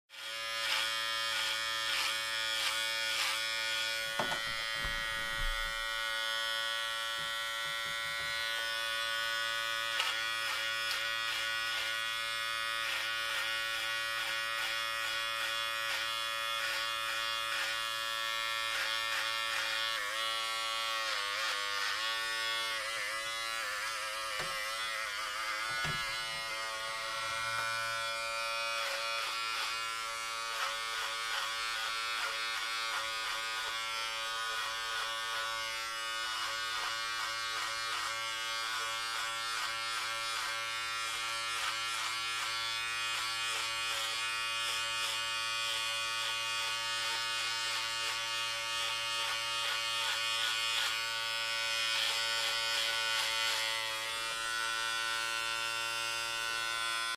Звуки машинки для стрижки
На этой странице собраны звуки работы машинки для стрижки волос в высоком качестве.
В коллекции представлены разные модели машинок: от тихих до более шумных, с различными режимами работы.